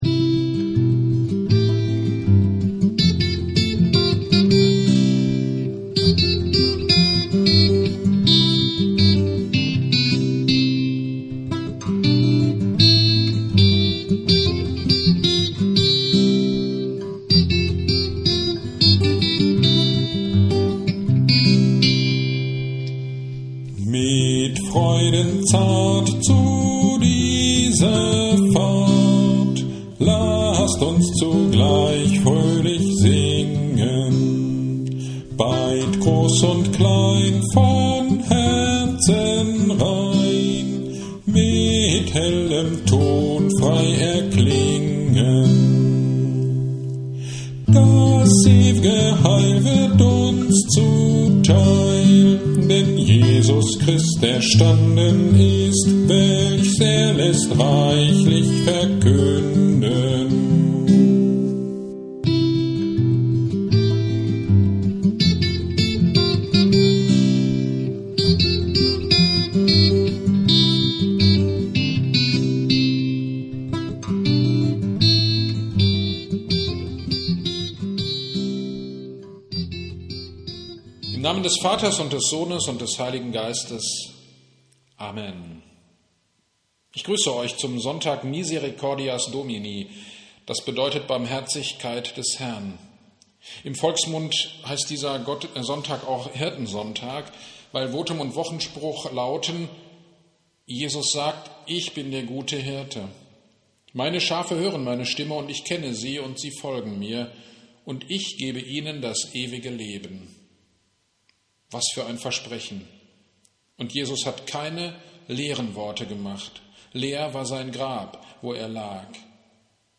Kirchgemeinde Pölzig